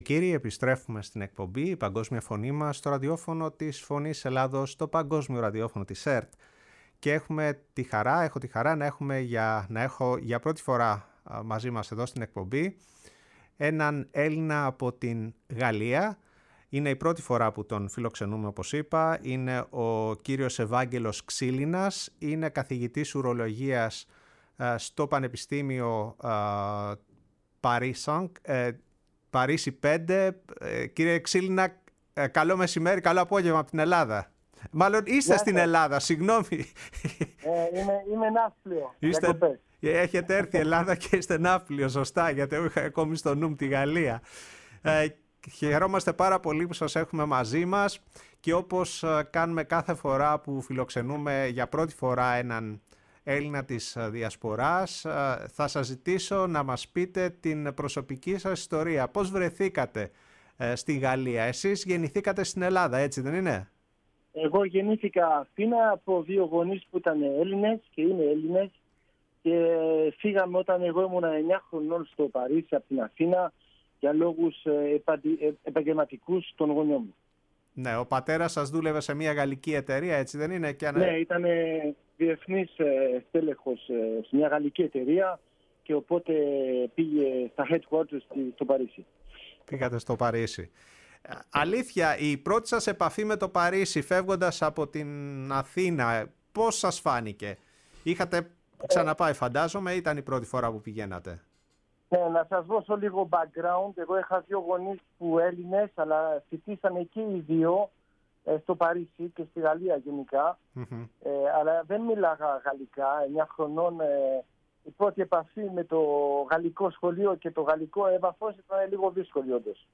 στην εκπομπή “Η Παγκόσμια Φωνή μας” στο ραδιόφωνο της Φωνής της Ελλάδας